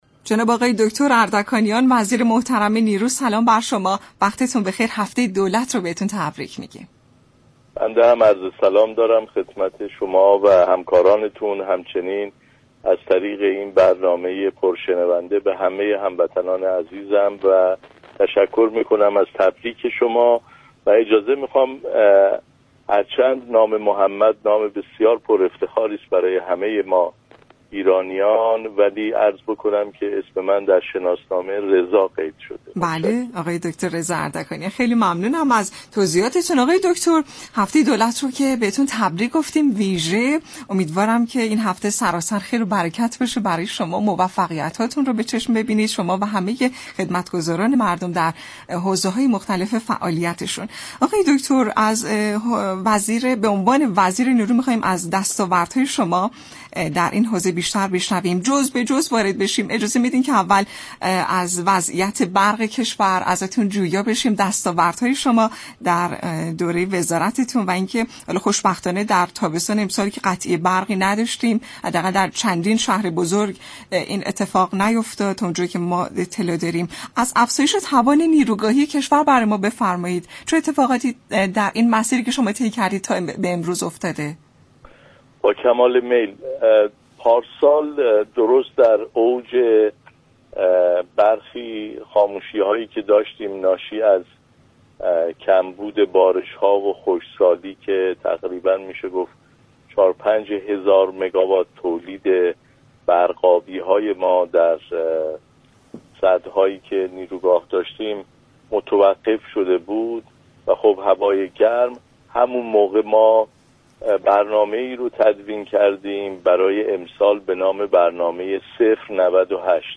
«رضا اردكانیان» وزیر نیرو در اولین روز هفته دولت در برنامه «سلام صبح بخیر» رادیو ایران گفت : امسال در تابستان 98 توانستیم ركوردهای خوبی را در صنعت برق با همكاری همه محقق كنیم.